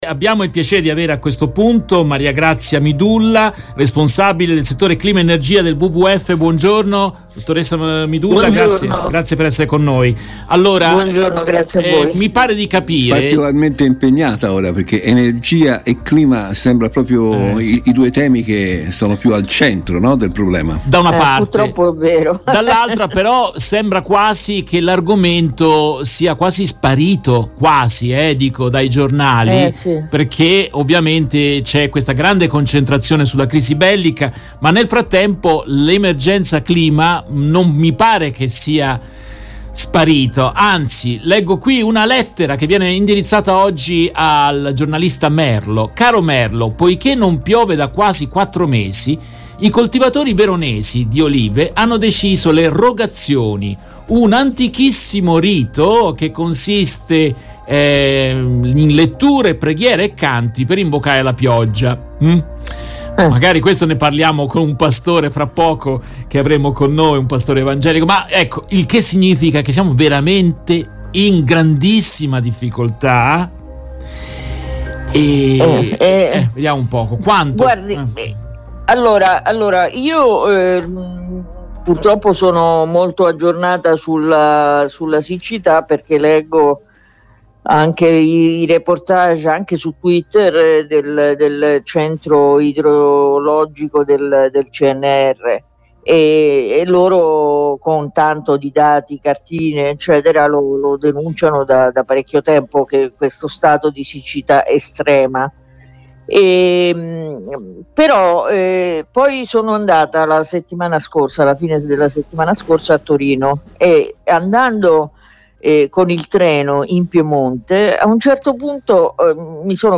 In questa intervista tratta dalla diretta RVS del 30 marzo 2022